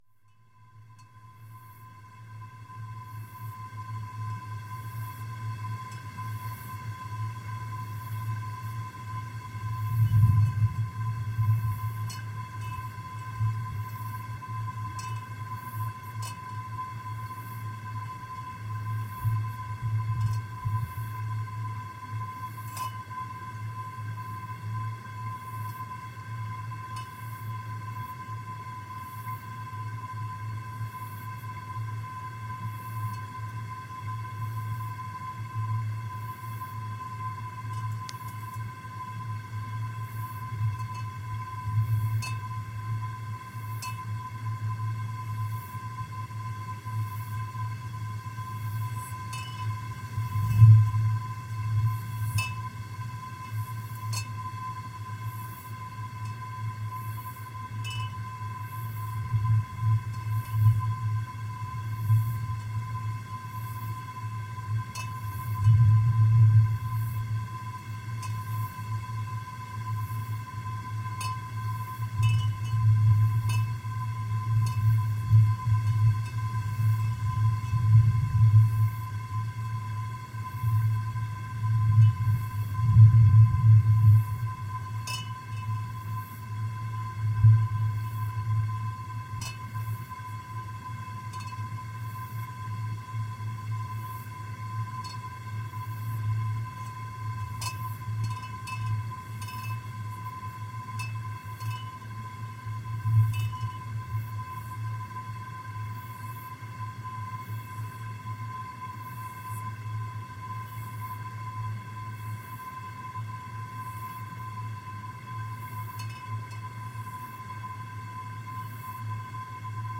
Gravação com microfones dentro de garrafas parcialmente submersas. Gravado com Fostex FR-2LE e um par de microfones Lavalier omni-direcionais Audio-Technica AT-899
NODAR.00186 – Microfones Dentro de Garrafas Submersas (Ameixiosa, São Pedro do Sul)
Tipo de Prática: Arte Sonora
Ameixiosa-Microfones-Dentro-de-Garrafas-Submersas.mp3